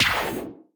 death_1.ogg